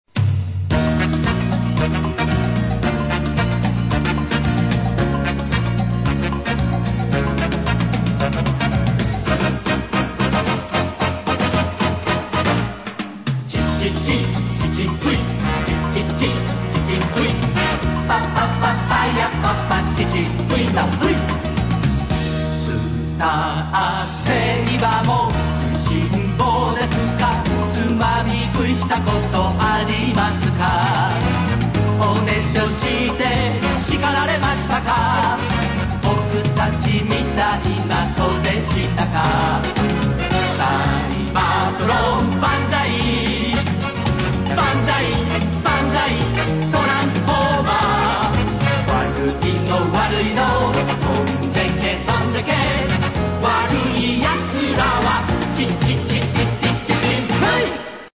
closing theme, my home versions.